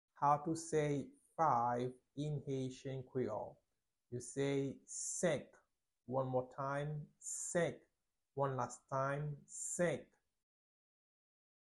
a native Haitian voice-over artist can be heard in the recording here or in the video below:
8.how-to-say-Five-in-haitian-creole-–-Senk-pronunciation.mp3